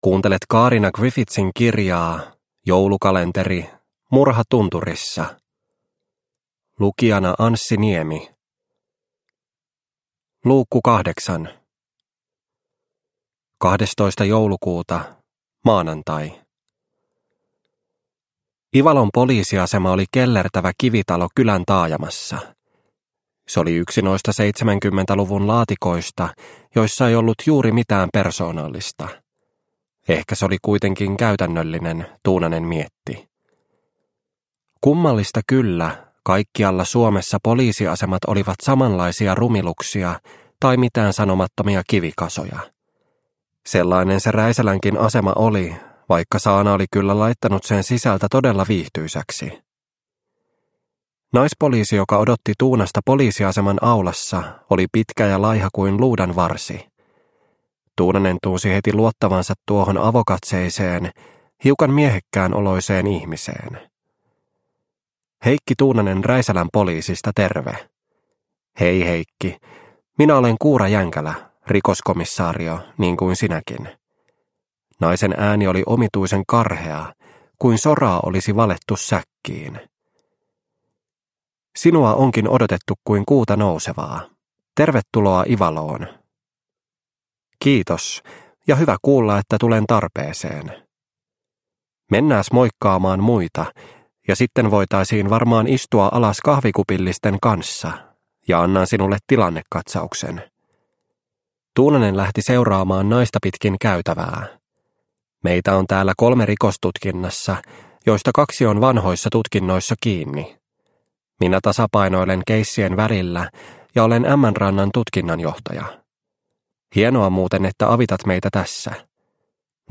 Murha tunturissa - Osa 8 – Ljudbok – Laddas ner